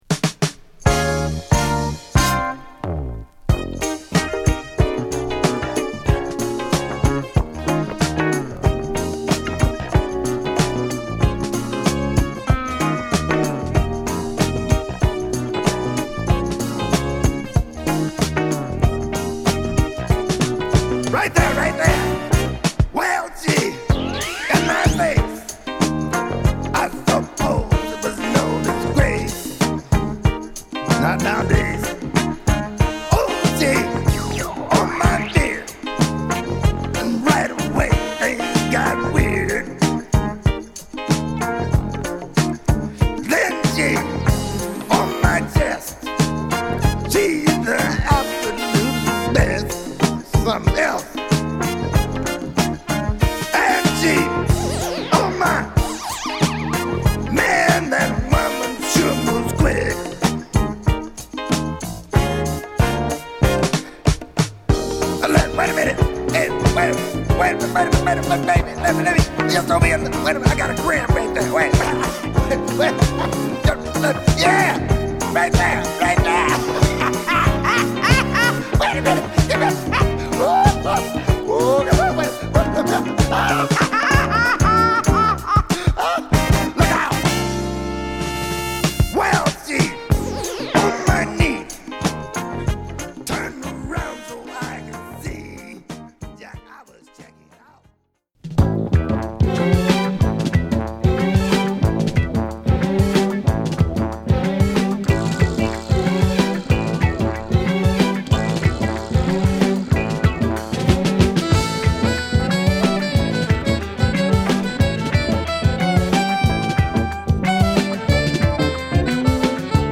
テキサス／ダラス出身のSax奏者